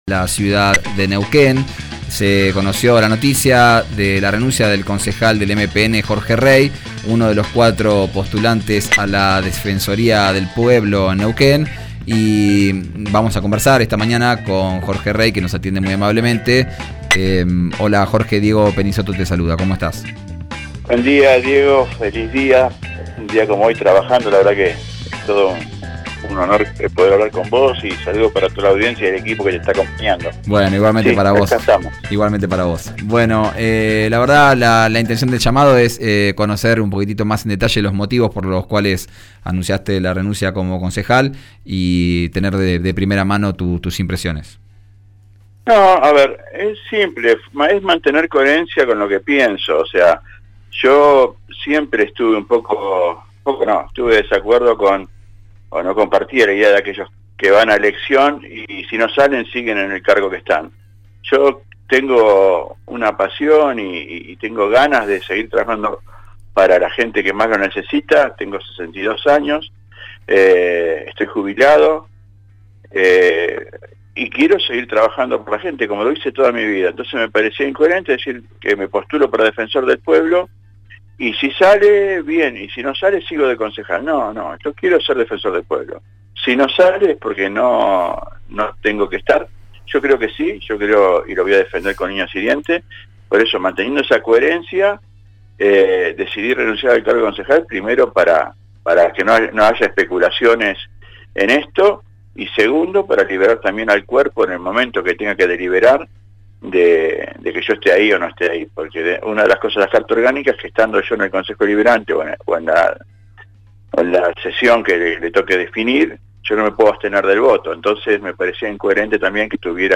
Escuchá a Jorge Rey en RÍO NEGRO RADIO: